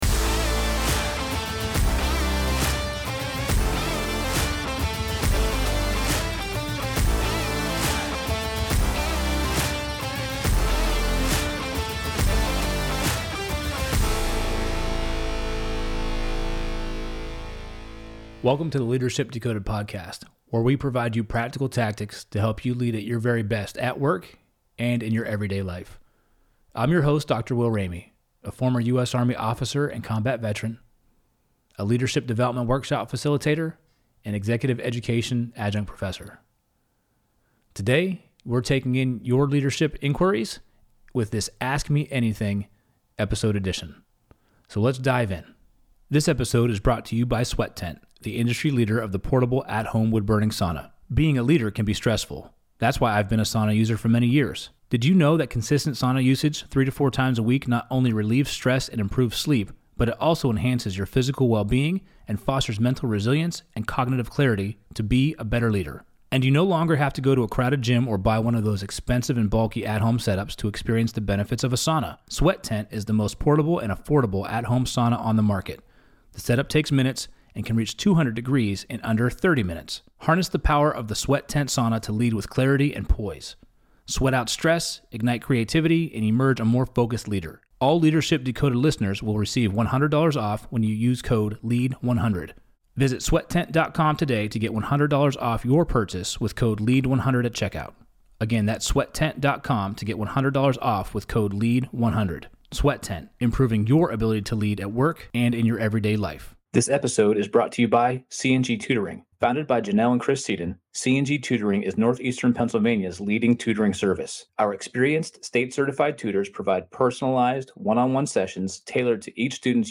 Ask Me Anything: Leadership Q&A | Ep. 054